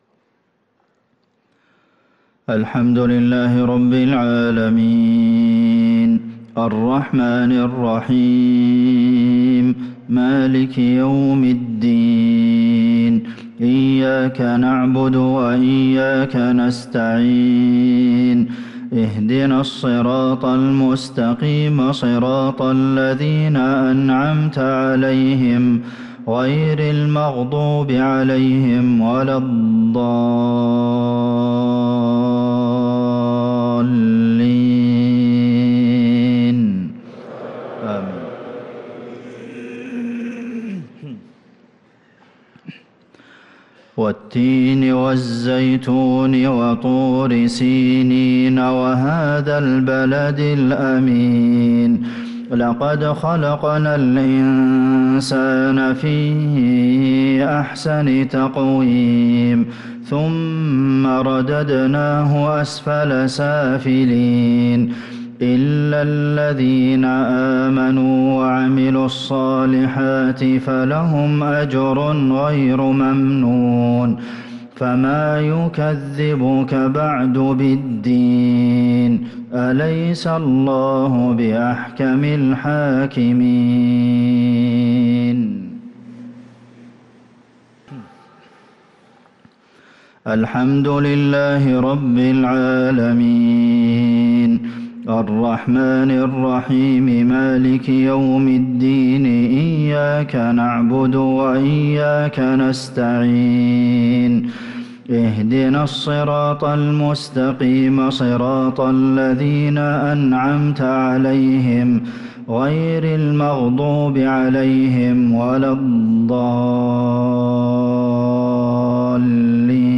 صلاة المغرب للقارئ عبدالمحسن القاسم 7 جمادي الآخر 1445 هـ
تِلَاوَات الْحَرَمَيْن .